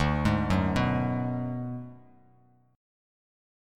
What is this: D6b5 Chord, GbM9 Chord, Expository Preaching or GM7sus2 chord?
D6b5 Chord